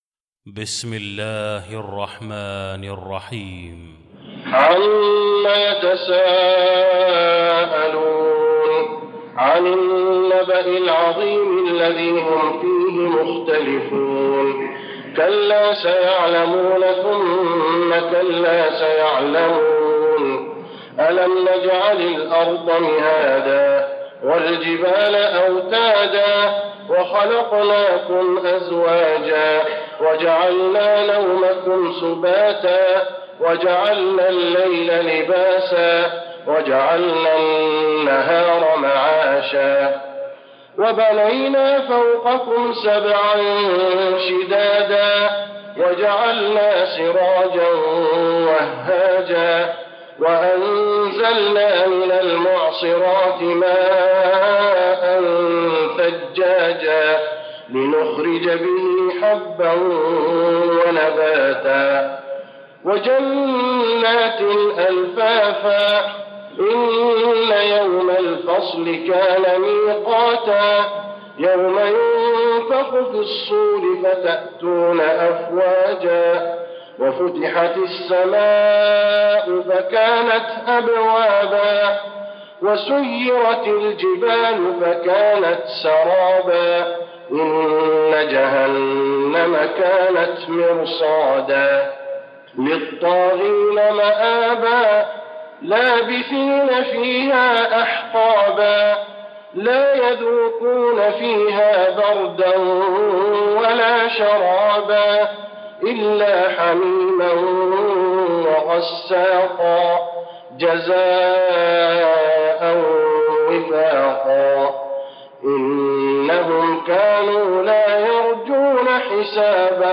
المكان: المسجد النبوي النبأ